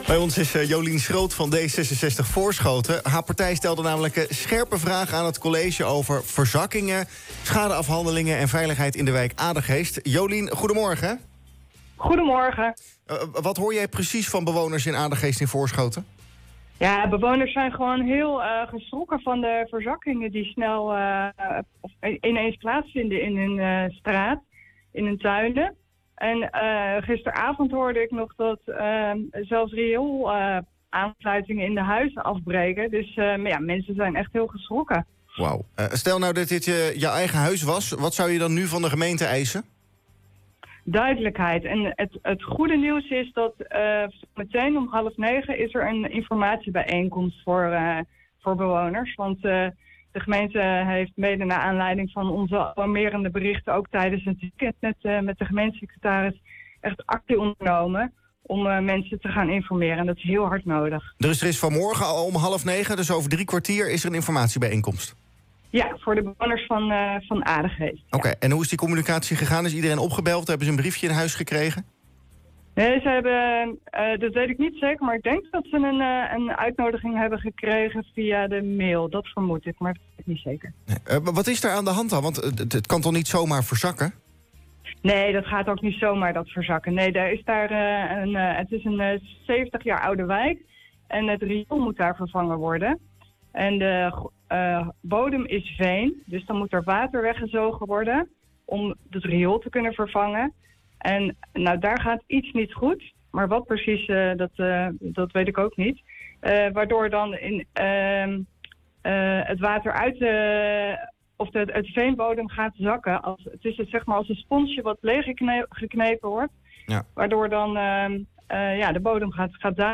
was maandag over dit onderwerp te horen in de ochtendshow van Centraal+.
te gast bij de Ochtendshow.